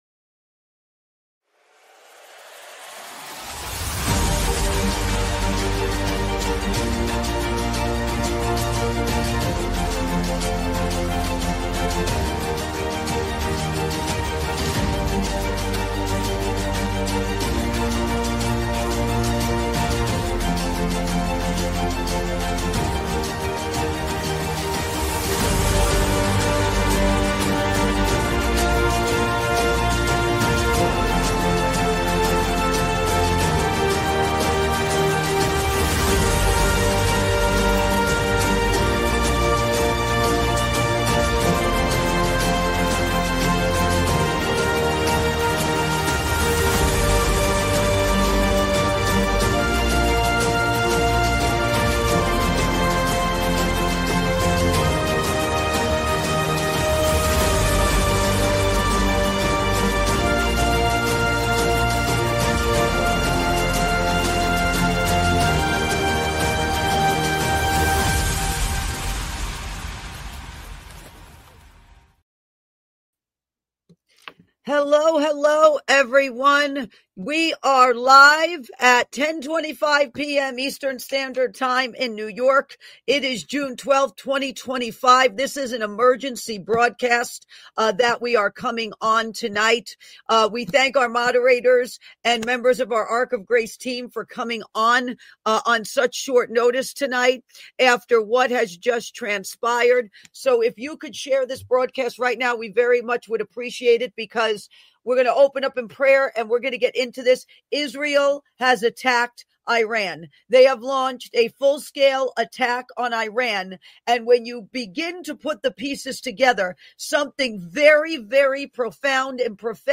Emergency Broadcast Israel Attacks Iran! Operation Rising Lion